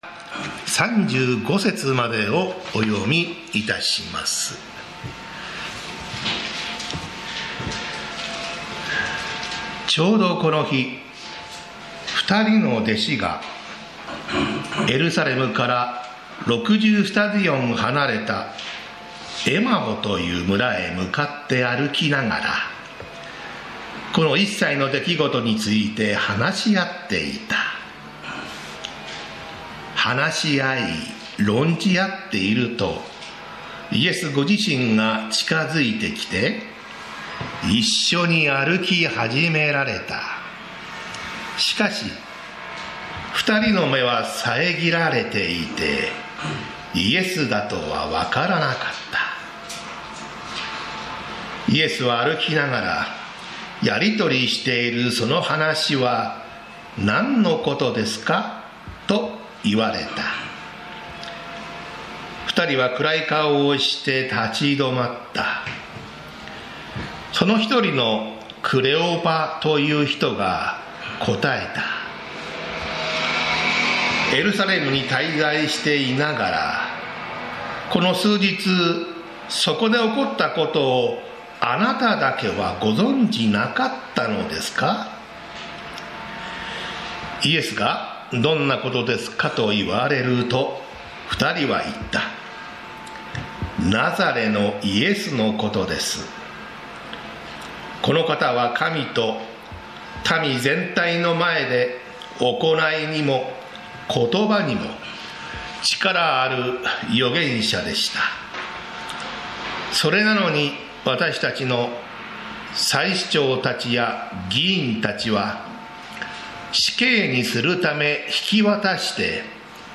礼拝説教アーカイブ 日曜 朝の礼拝